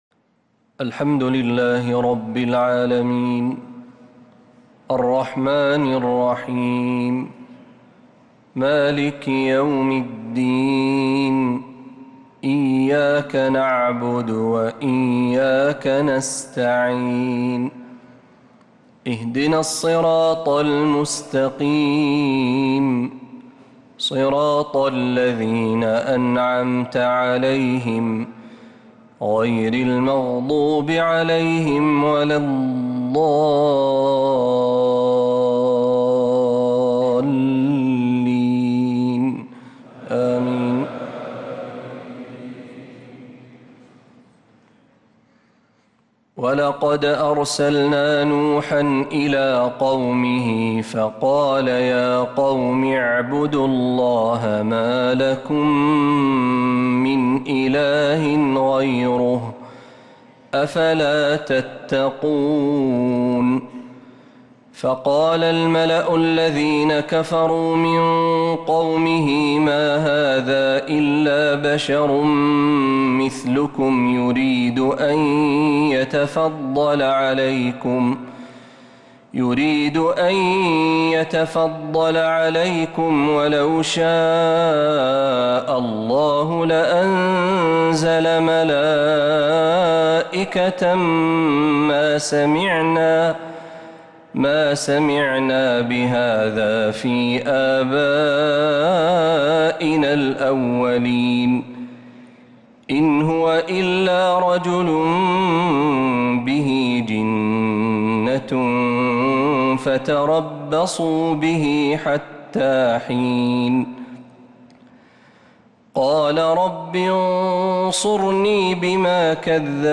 صلاة العشاء للقارئ محمد برهجي 9 جمادي الأول 1446 هـ
تِلَاوَات الْحَرَمَيْن .